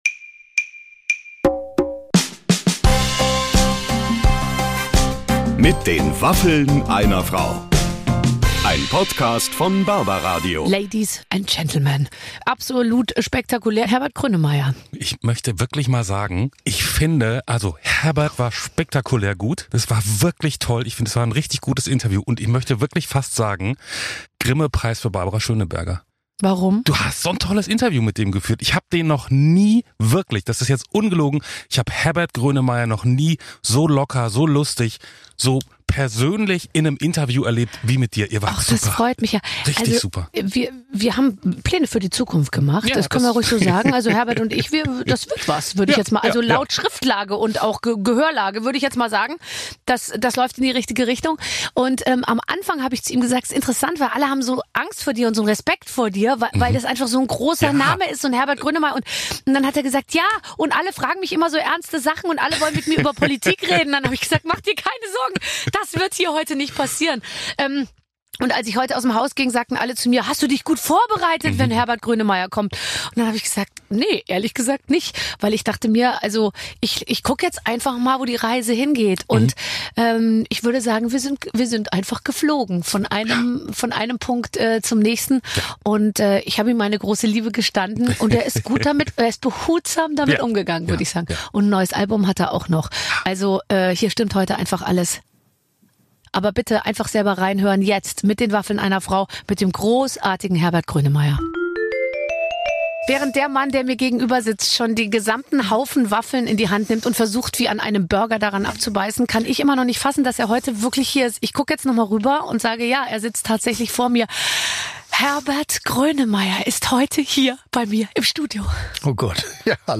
Wir können es selbst noch gar nicht glauben, aber es ist wahr: Herbert Grönemeyer ist wirklich bei uns im Podcast! Und er freut sich sogar richtig, ein wenig mit Barbara Schöneberger zu plaudern. Wir wissen jetzt, ob wir ihn auch mal im Berghain antreffen können, wofür er gerne sein Geld ausgibt und wie sein neuestes Album entstanden ist.